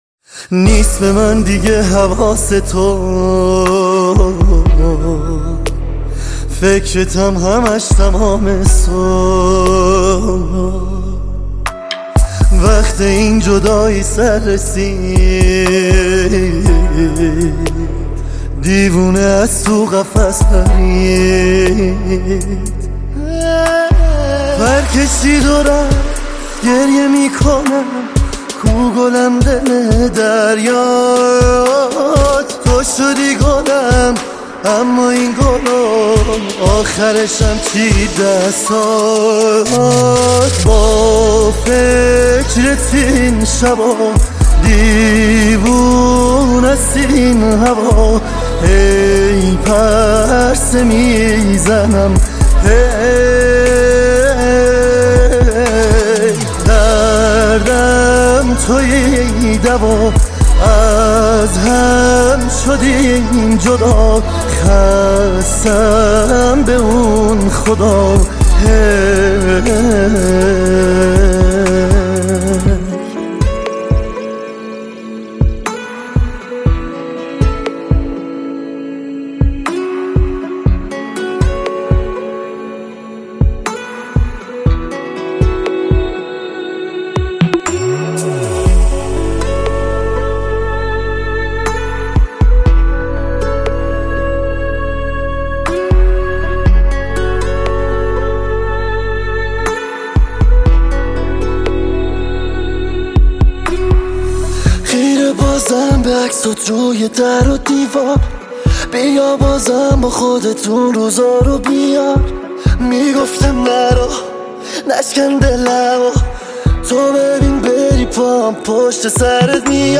دانلود آهنگ شاد با کیفیت ۱۲۸ MP3 ۴ MB